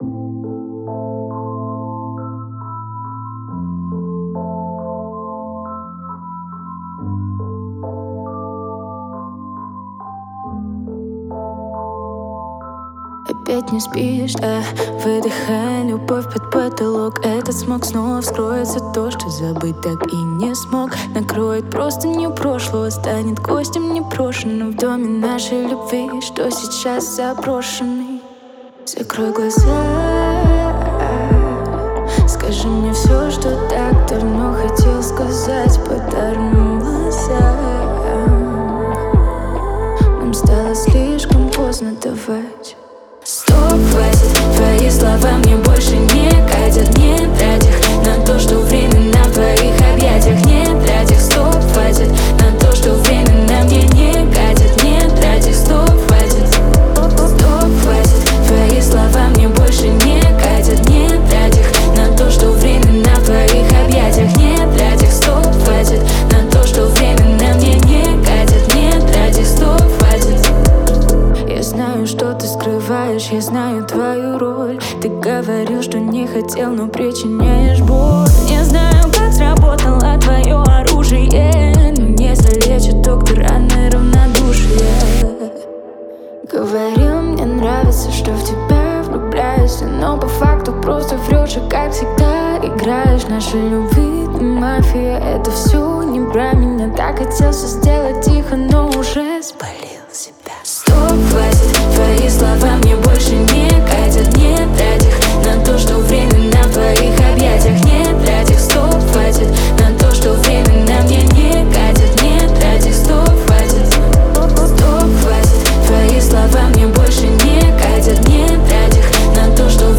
эмоциональная поп-песня